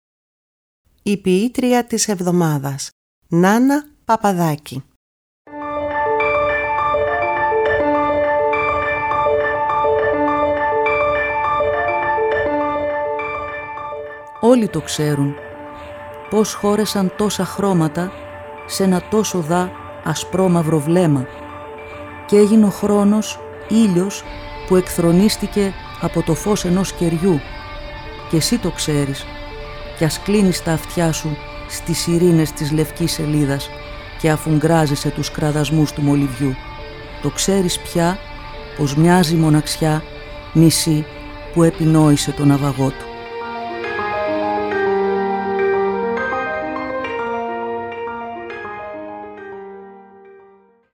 Κάθε εβδομάδα είναι αφιερωμένη σ’ έναν σύγχρονο Έλληνα ποιητή ή ποιήτρια, ενώ δεν απουσιάζουν οι ποιητές της Διασποράς. Οι ίδιοι οι ποιητές και οι ποιήτριες επιμελούνται τις ραδιοφωνικές ερμηνείες. Παράλληλα τα ποιήματα «ντύνονται» με πρωτότυπη μουσική, που συνθέτουν και παίζουν στο στούντιο της Ελληνικής Ραδιοφωνίας οι μουσικοί της Ορχήστρας της ΕΡΤ, καθώς και με μουσικά κομμάτια αγαπημένων δημιουργών.